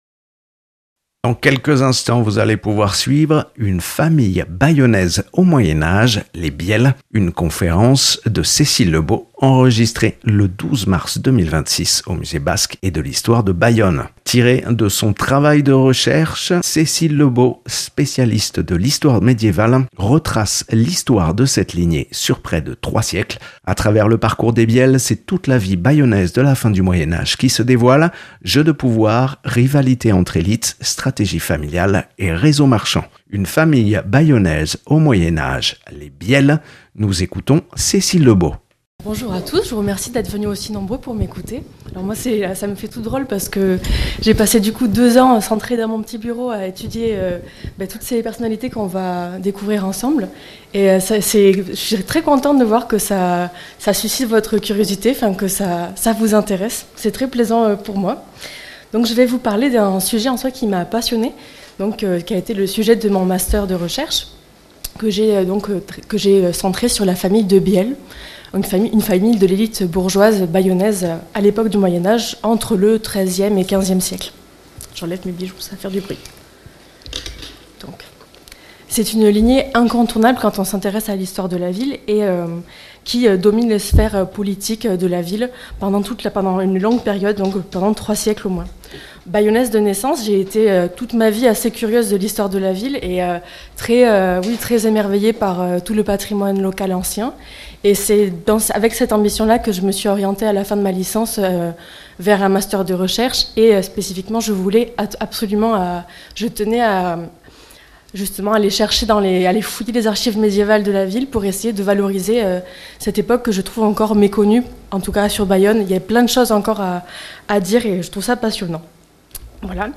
(enregistrement réalisé le 12 mars 2026 au Musée Basque et de l’histoire de Bayonne ) Une famille bayonnaise au Moyen-Âge, les Bielle La famille Bielle a marqué de son empreinte la ville de Bayonne au Moyen-Âge.